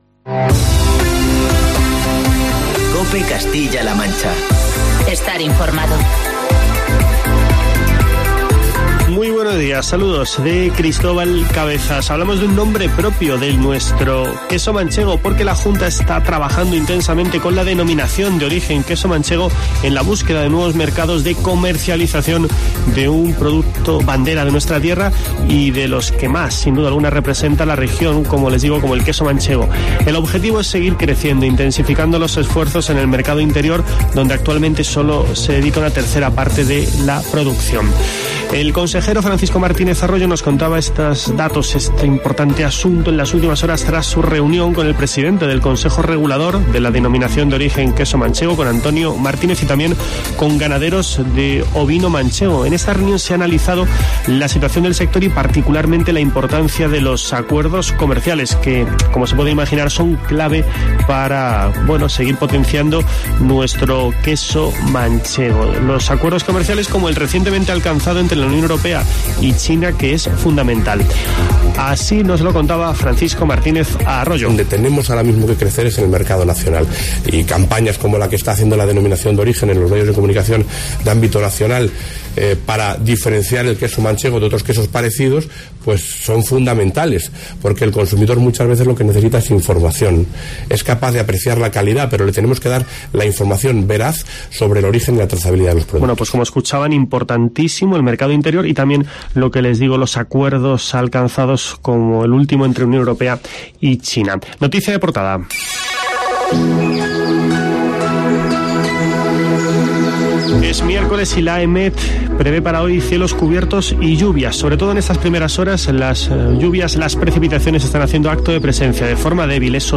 Escucha en la parte superior de esta noticia el boletín informativo matinal de COPE Castilla-La Mancha y COPE Toledo de este miércoles, 13 de noviembre.